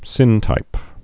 (sĭntīp)